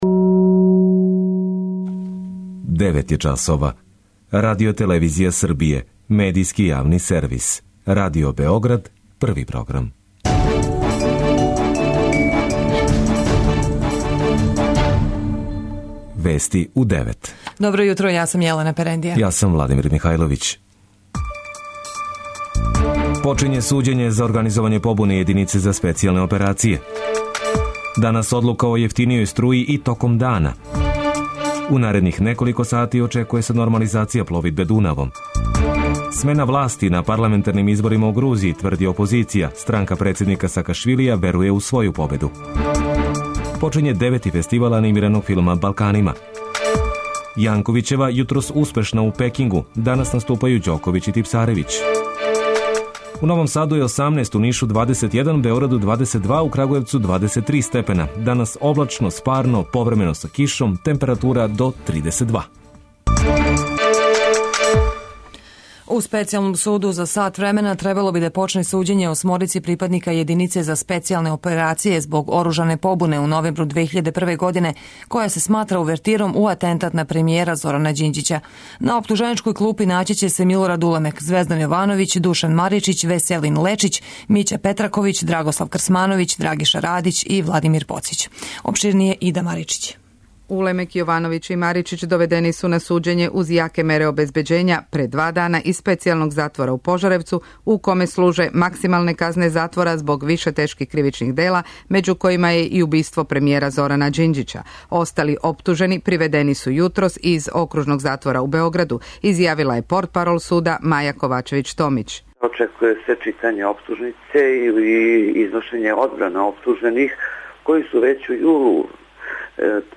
преузми : 9.62 MB Вести у 9 Autor: разни аутори Преглед најважнијиx информација из земље из света.